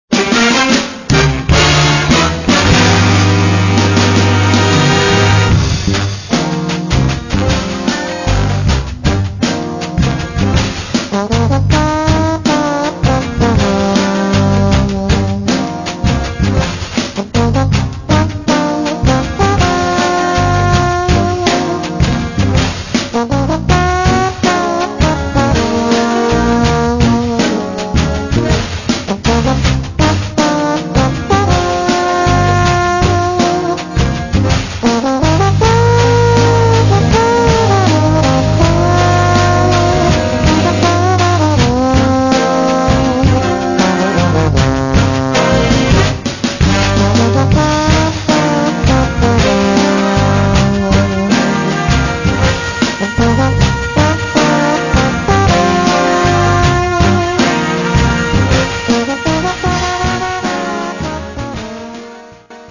Gattung: Solo für Posaune und Blasorchester
Besetzung: Blasorchester